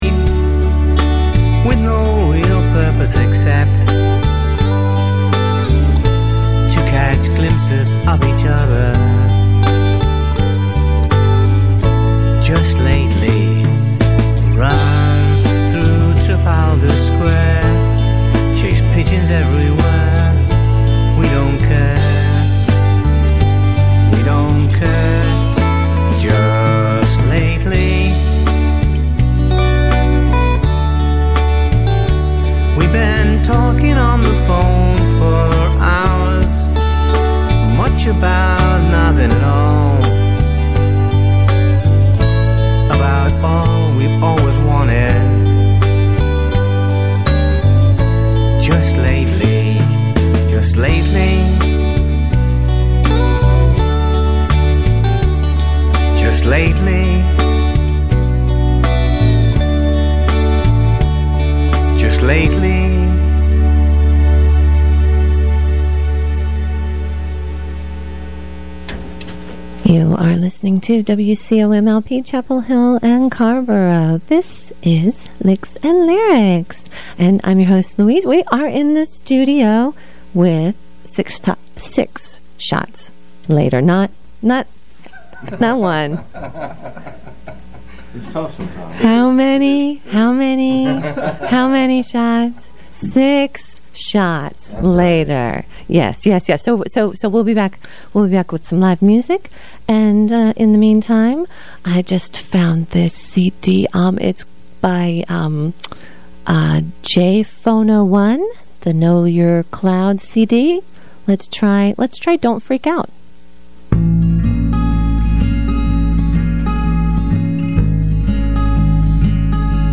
radio , Raleigh band